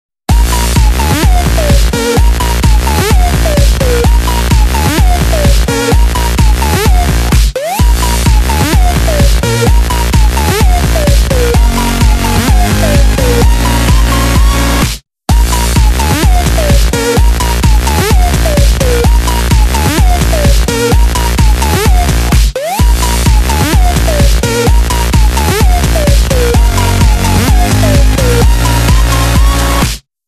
DJ铃声, M4R铃声, MP3铃声 60 首发日期：2018-05-15 15:19 星期二